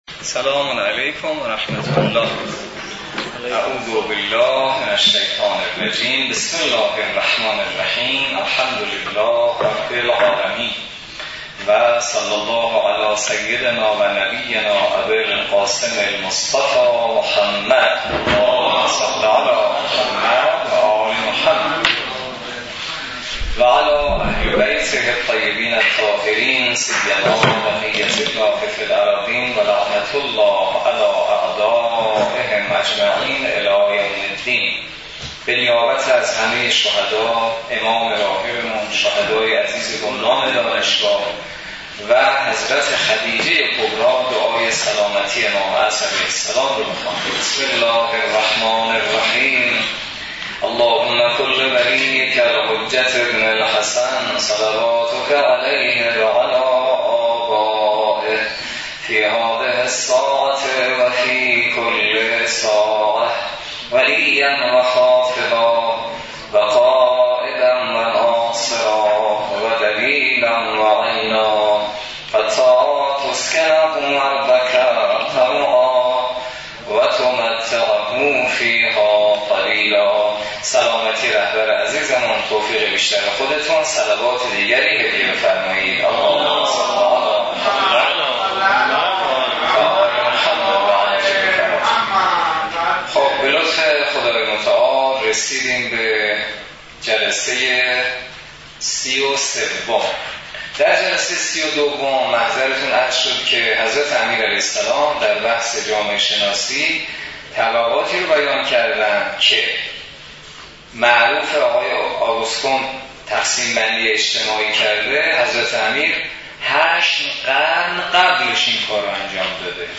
برگزاری سی و سومین جلسه تفسیر نامه ۵۳ نهج البلاغه توسط نماینده محترم ولی فقیه و در دانشگاه کاشان
سی و سومین جلسه تفسیر نامه ۵۳ نهج البلاغه توسط حجت‌الاسلام والمسلمین حسینی نماینده محترم ولی فقیه و امام جمعه کاشان در دانشگاه کاشان برگزار گردید.